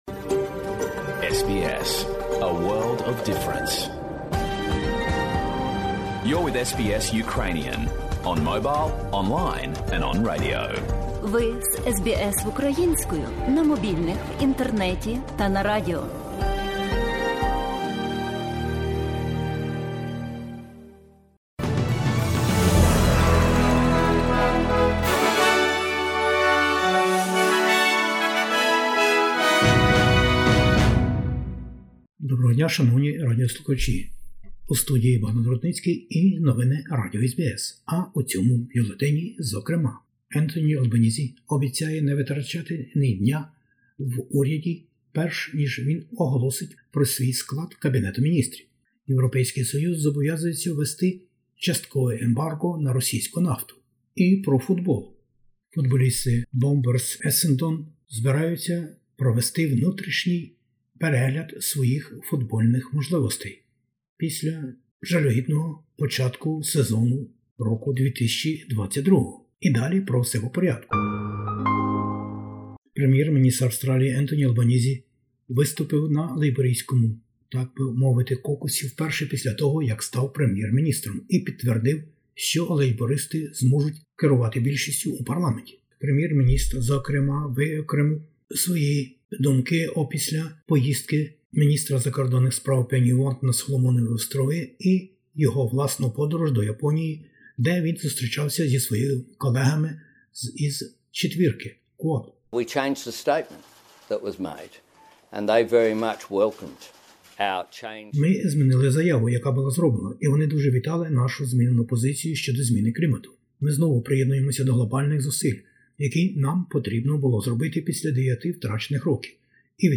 SBS новини українською - 31/05/2022